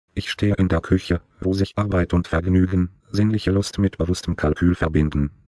Die folgenden Beispiele desselben Satzes, der von verschiedenen deutschen Text-to-Speech-Sprachsynthesen generiert wurde, können das illustrieren.
Diese verschiedenen Synthesen deuten auf verschiedene Modelle hin.